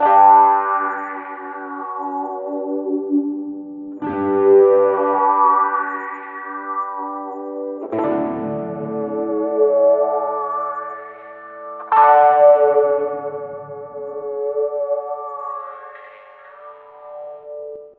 电吉他 " wah two 电吉他
描述：两把电吉他，带超频，华音，使用颤音或颤音臂。一把吉他在左边，另一把在右边。循环在120 bpm。// Dos guitarras eléctricas con overdrive, wahwah y usando la palanca de vibrato.一支在左手边，另一支在右手边。这是一个循环，速度为120 bpm。
Tag: 120-BPM 畸变 吉他